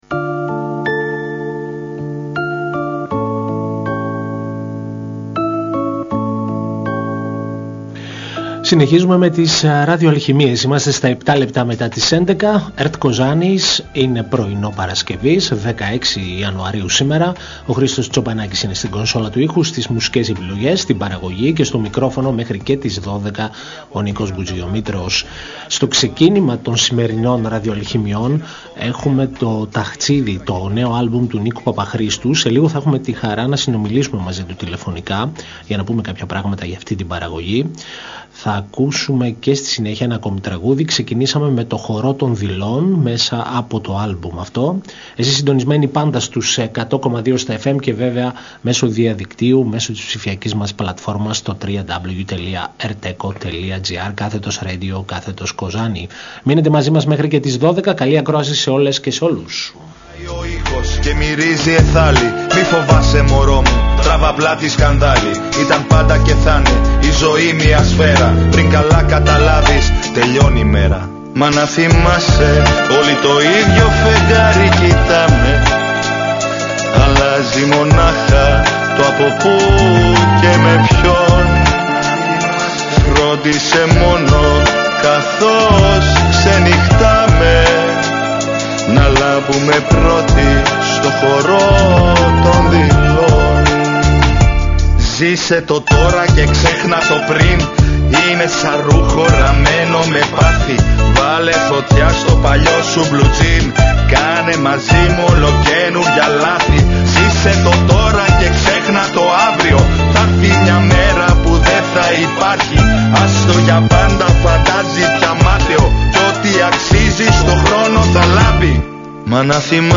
Συνέντευξη
Μια εκπομπή μουσικής και λόγου διανθισμένη με επιλογές από την ελληνική δισκογραφία.